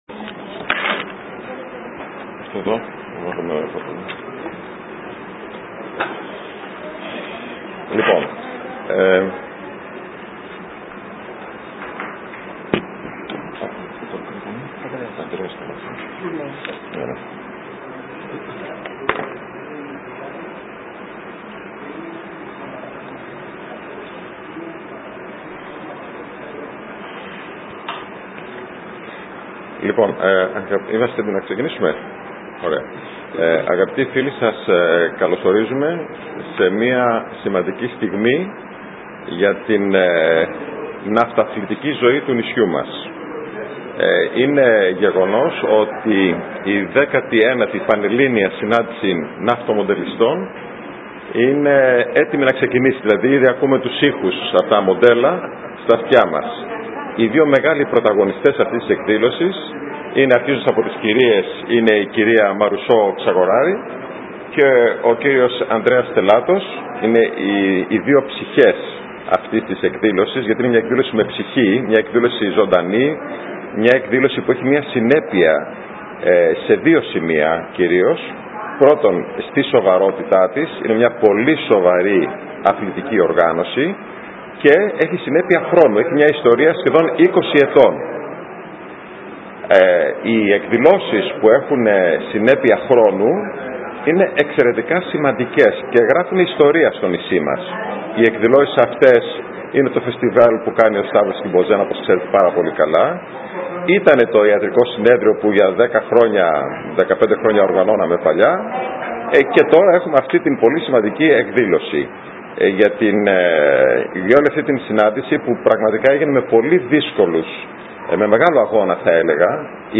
Συνέντευξη τύπου για την 19η Πανελλήνια Συνάντηση Ναυτομοντελιστών | Kefalonia News